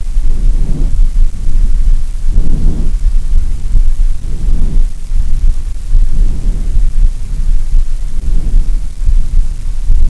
Chest Auscultation
Click on images to hear chest sounds